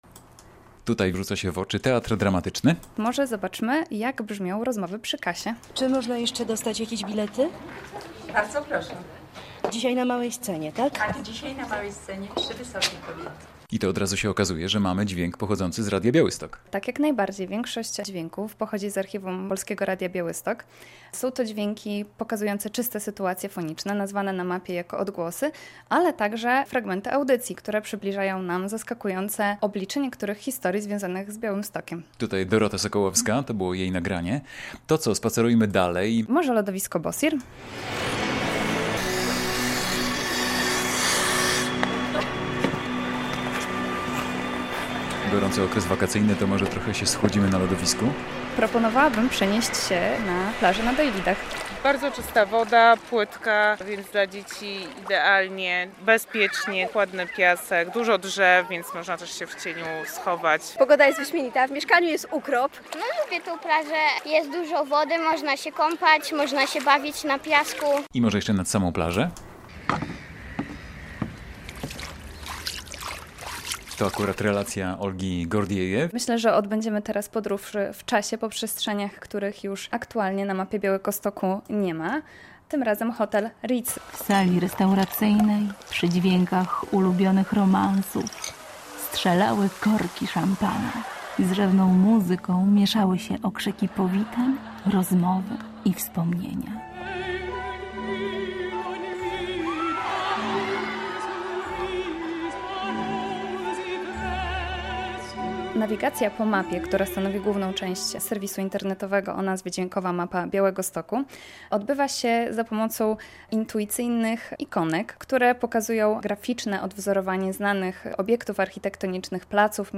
Ze współautorką Dźwiękowej Mapy Białegostoku